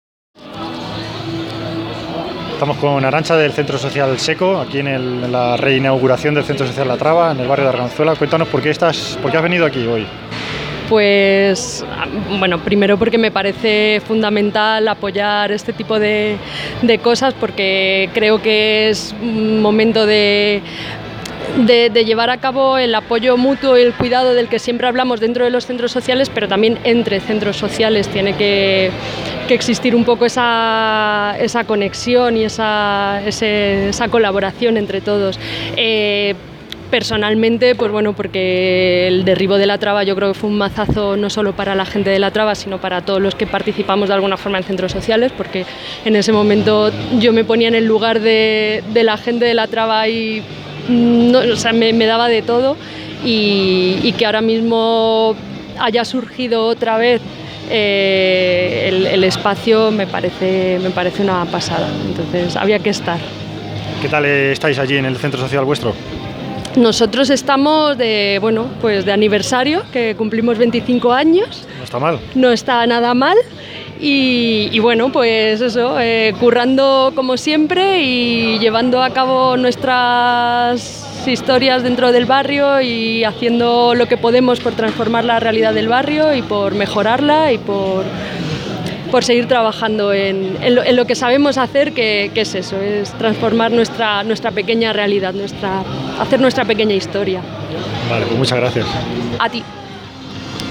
Entrevistas durante la re-inauguración del CSO La Traba
Entrevista